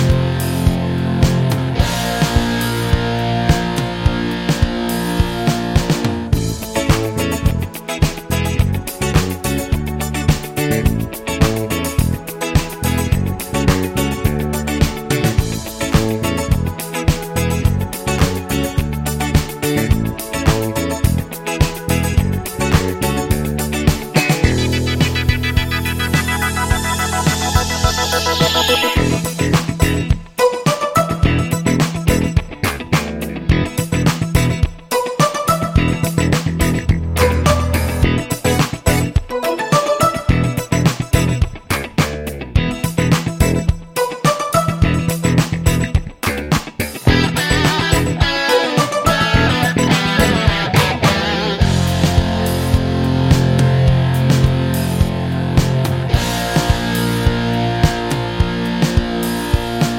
no Backing Vocals Pop (1980s) 3:18 Buy £1.50